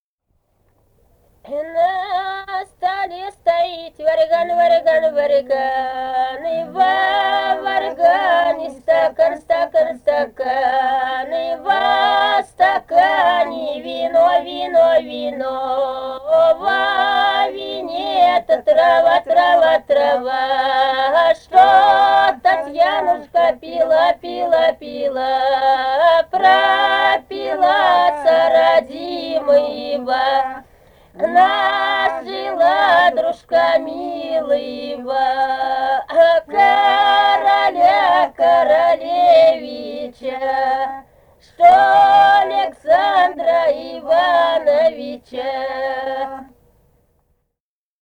полевые материалы
Самарская область, с. Усманка Борского района, 1972 г. И1316-16